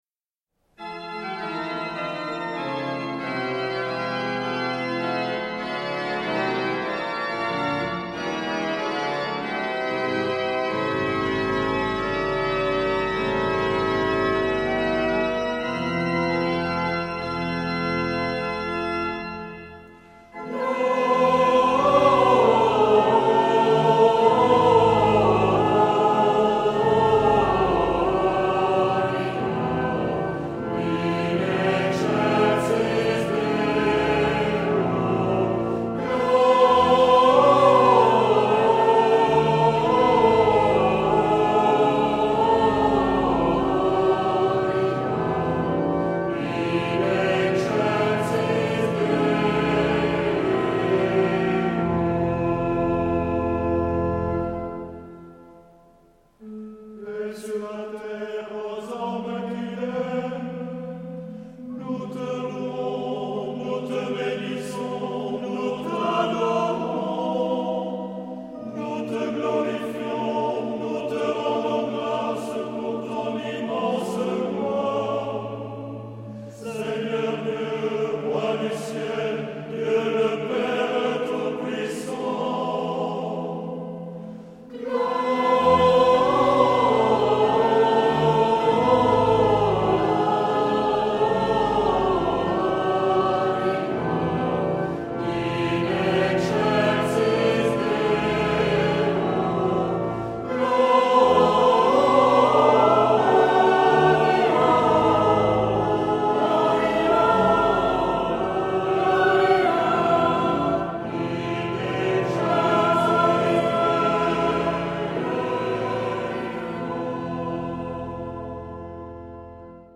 Genre-Style-Forme : Messe
Caractère de la pièce : joyeux
Type de choeur : TTB  (3 voix égales d'hommes OU unisson )
Solistes : Soprano (1) ad libitum  (1 soliste(s))
Instrumentation : Orgue  (1 partie(s) instrumentale(s))
Tonalité : fa majeur